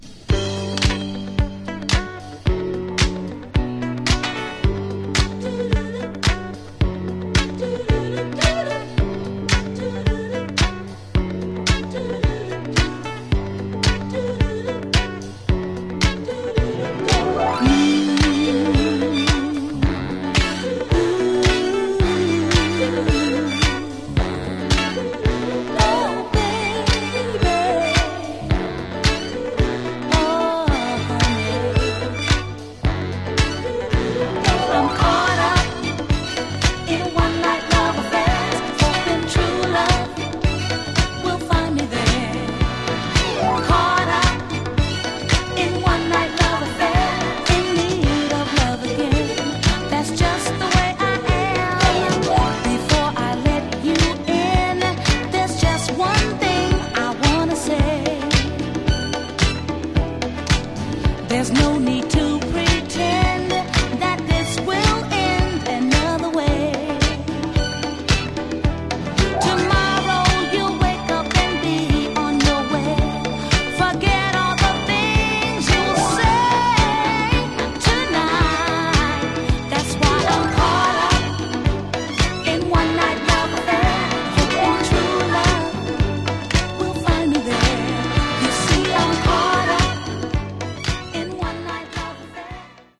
Genre: Northern Soul, Philly Style
This terrific Philly-style dancer was their biggest hit.